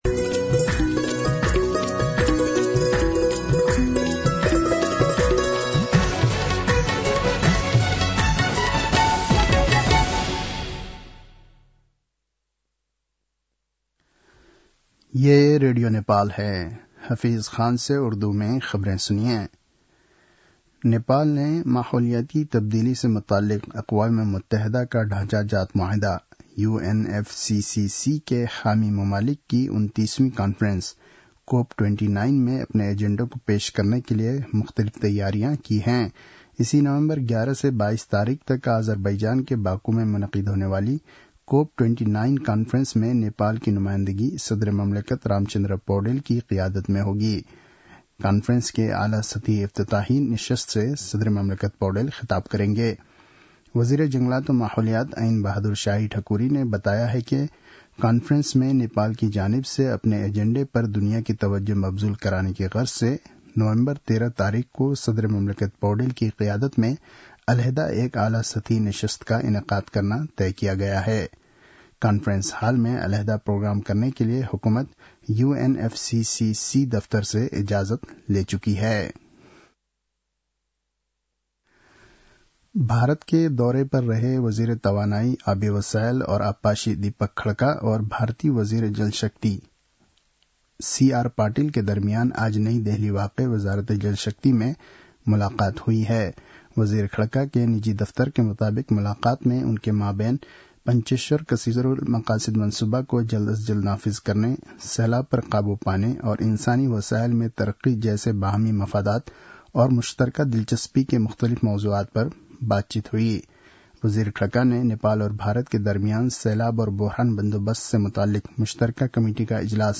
An online outlet of Nepal's national radio broadcaster
उर्दु भाषामा समाचार : २१ कार्तिक , २०८१